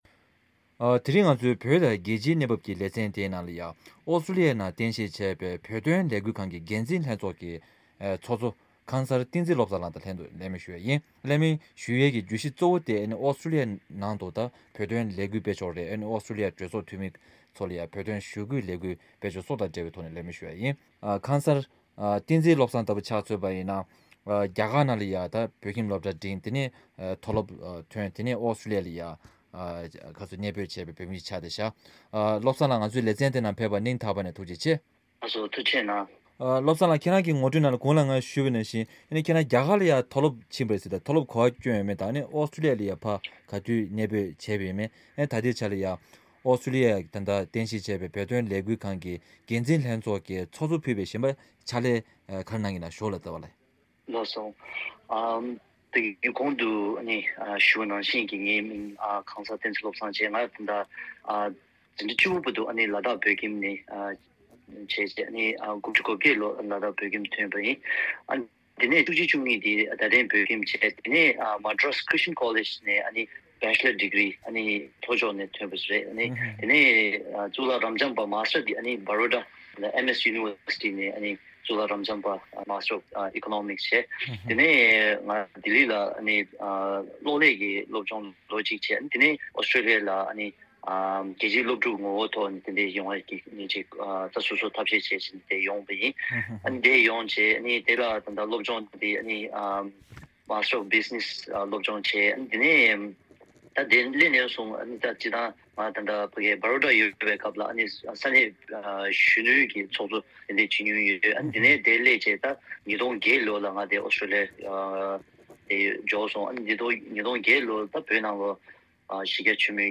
ཨོ་གླིང་ནང་བོད་དོན་གནས་སྟངས་དང་ལས་འགུལ་ཐད་གླེང་མོལ་ཞུས་པ།